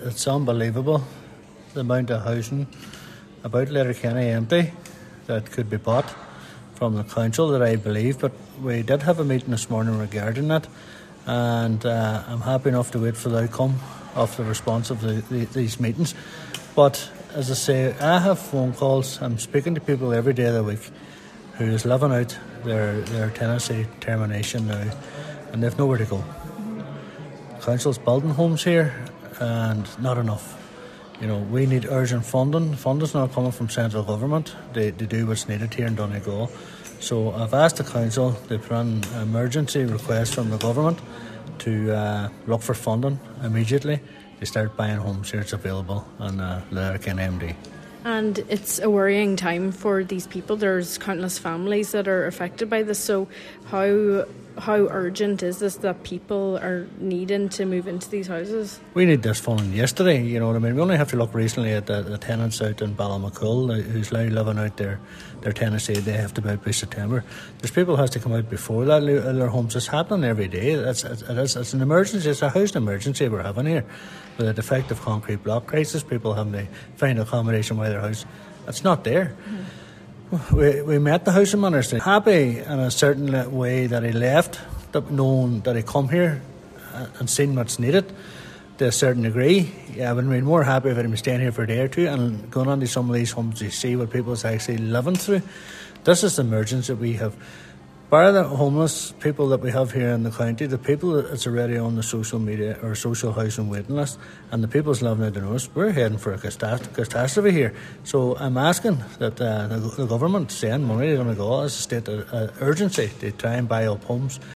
A Letterkenny Councillor says the number of vacant houses in Letterkenny is unacceptable when families in the town are receiving eviction notices.